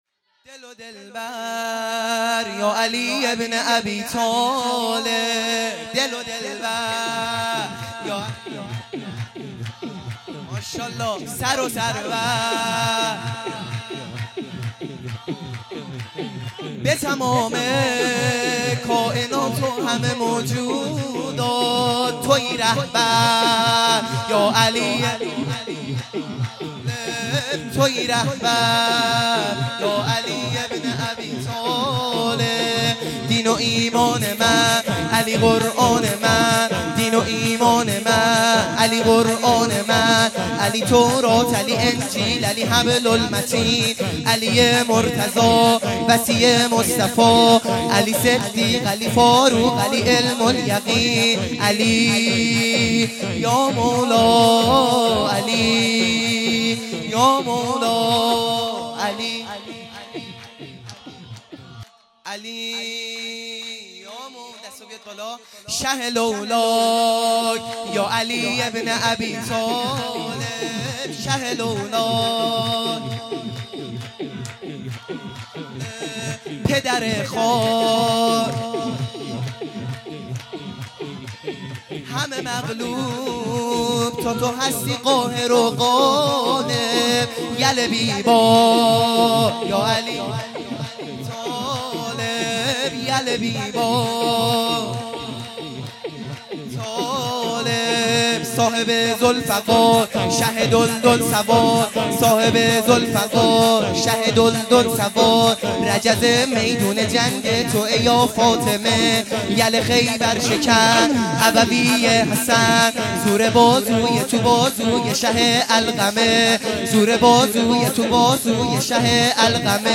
سرود | دل دلبر یا علی